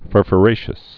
(fûrfə-rāshəs, -fyə-)